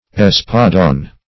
Search Result for " espadon" : The Collaborative International Dictionary of English v.0.48: Espadon \Es"pa*don\, n. [F. espadon, fr. Sp. espadon, fr. espada sword; or fr. It. spadone an espadon, spada sword.] A long, heavy, two-handed and two-edged sword, formerly used by Spanish foot soldiers and by executioners.